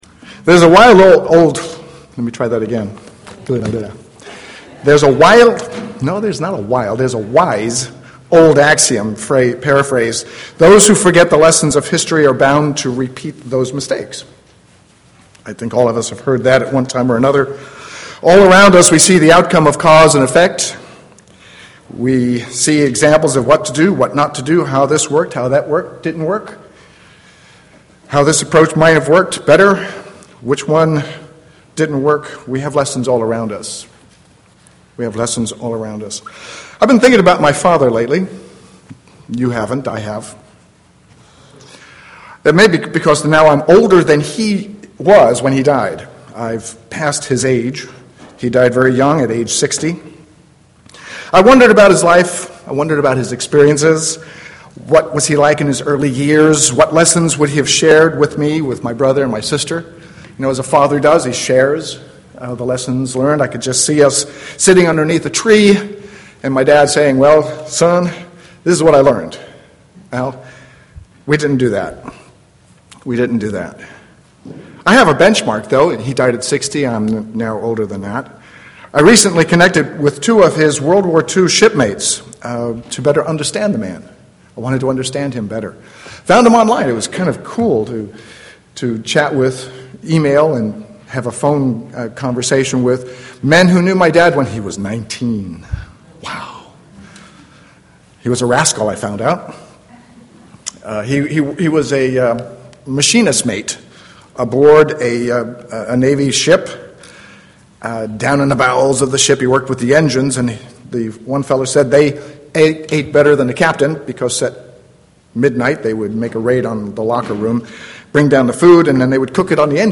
Given in San Jose, CA
UCG Sermon Studying the bible?